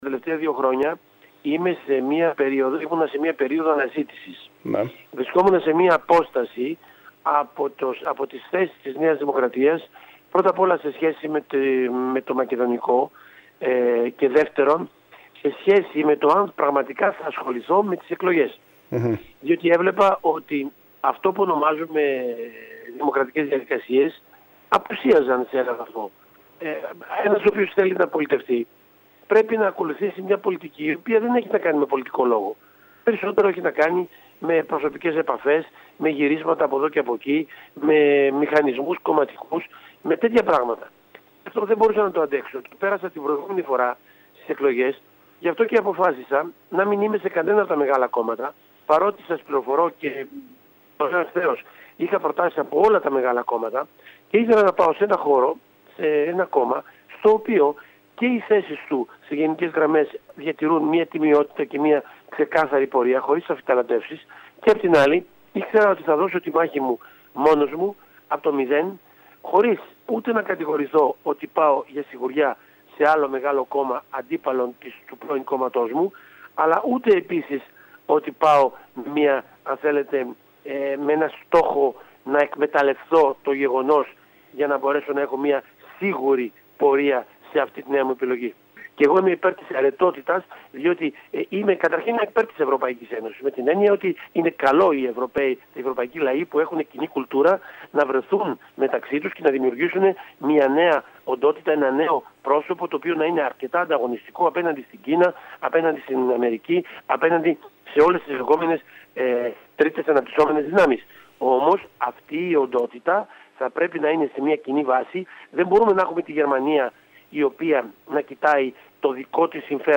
Περιφερειακοί σταθμοί ΚΕΡΚΥΡΑ
Ο κ. Δαββέτας μίλησε στην ΕΡΤ για τους λόγους που τον οδήγησαν στην απομάκρυνση από τη Νέα Δημοκρατία αλλά και το διακύβευμα των ευρωεκλογών που δεν είναι άλλο από την αύξηση της ευρωπαϊκής συνοχής και την ανάσχεση της ακροδεξιάς. Ο κ. Δαββέτας επισήμανε ότι θα πρέπει να λάβουν χαρακτηριστικά αιρετής διάρθρωσης, σημαντικά όργανα και θεσμοί της Ε.Ε. οι οποίοι σήμερα λαμβάνουν κρίσιμες αποφάσεις, χωρίς να λογοδοτούν στην ουσία στους ευρωπαίους πολίτες.